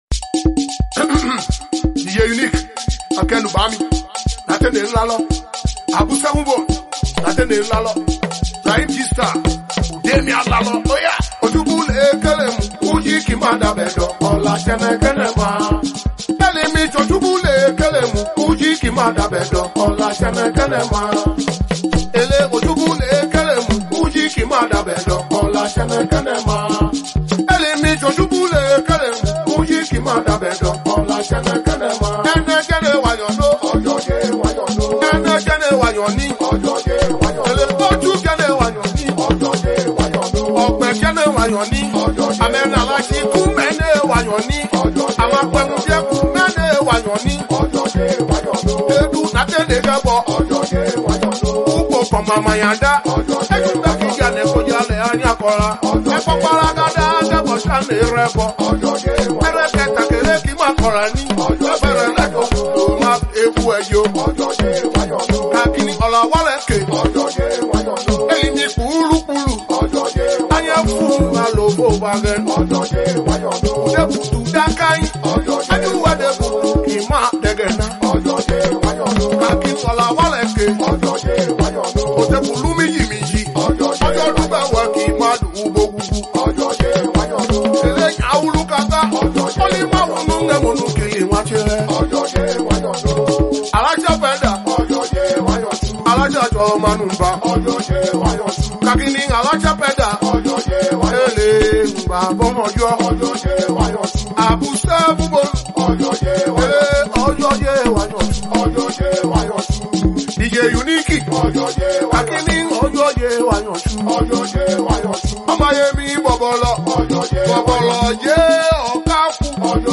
cultural song